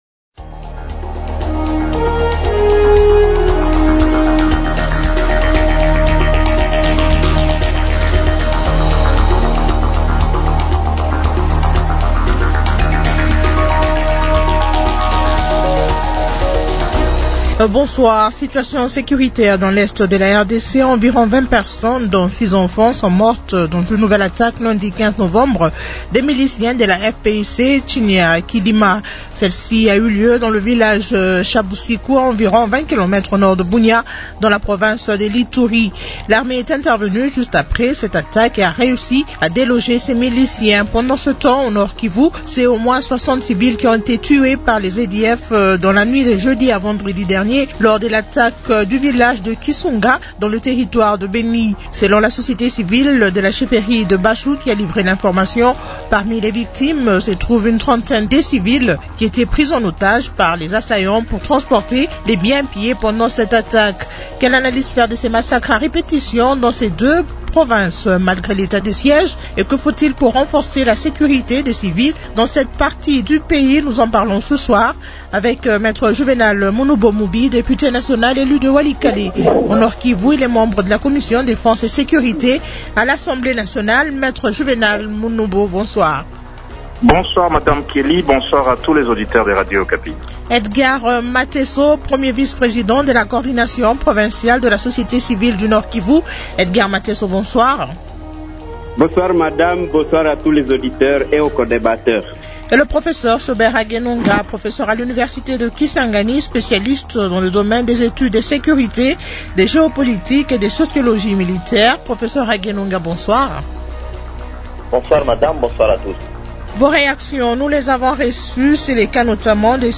Invités : -Me Juvénal Munubo Mubi, député national élu de Walikale au Nord-Kivu.